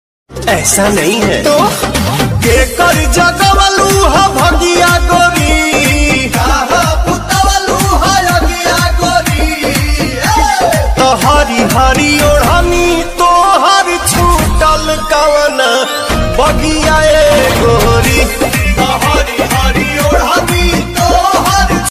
bhojpuri romantic